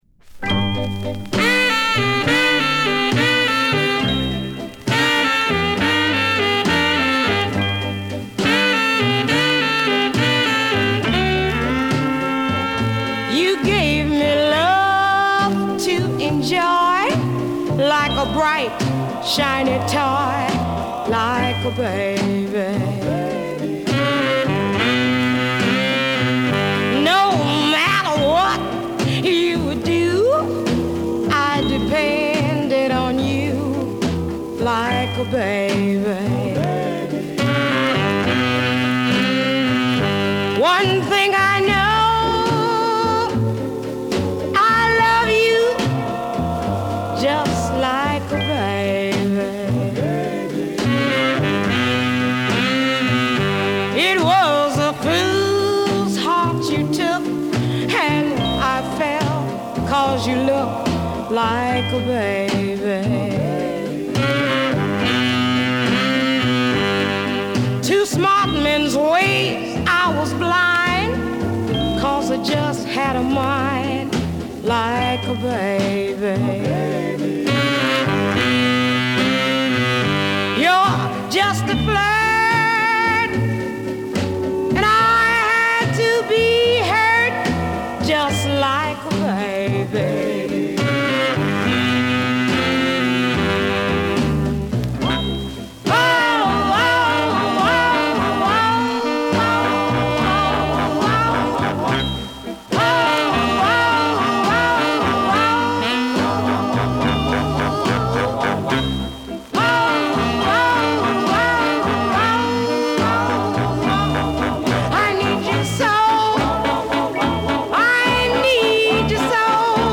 決して上手とはいえないが、活気のある歌声を聴かせてくれる。表現豊かなサックス・プレイも。